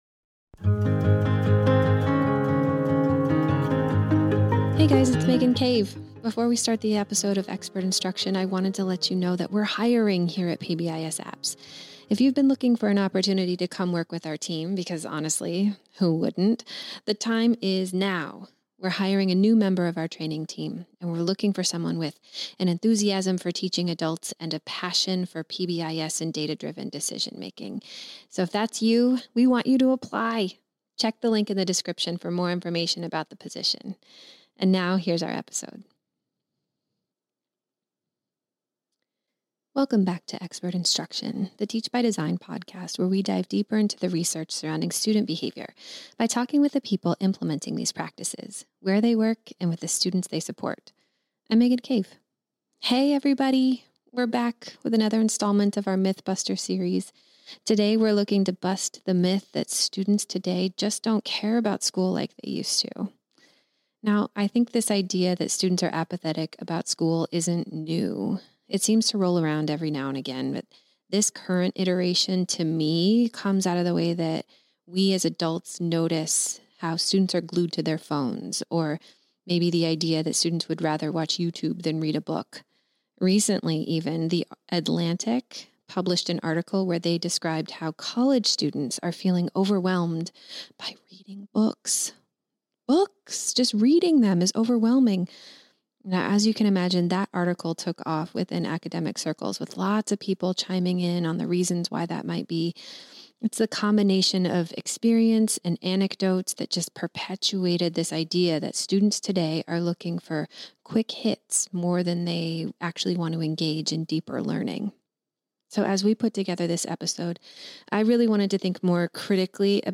Expert Instruction is the PBISApps Teach by Design podcast where we talk to experts from the educational field - researchers, practitioners, community members - about their work. Each episode connects with that month’s Teach by Design article, taking you deeper into your own exploration of the topic.